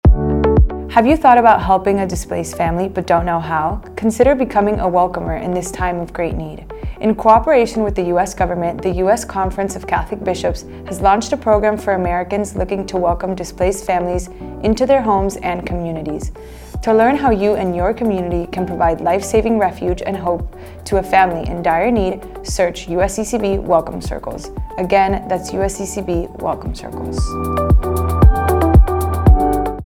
30 Second PSA
Welcome Circles 30 Female.mp3